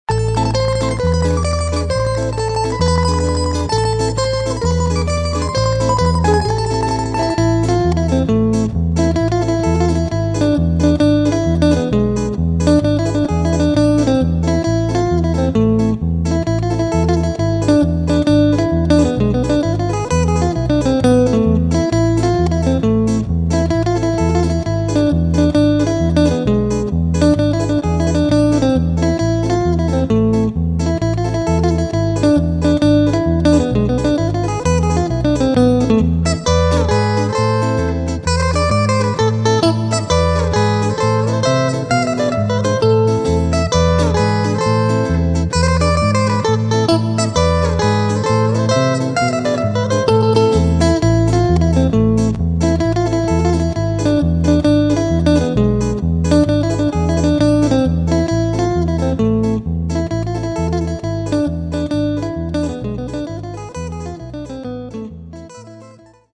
Tarantella.